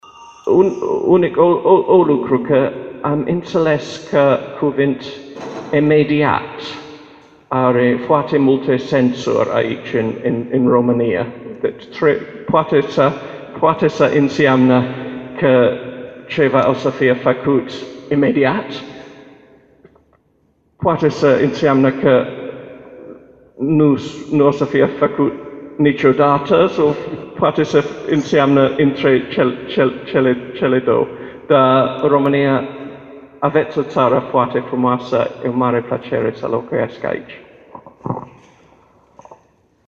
Conferința ambasadorului Marii Britanii, Paul Brummell( foto) pe teme de istorie, diplomație, dar și sociale, s-a desfășurat aștăzi în Sala a Voievozilor a Palatului Culturii.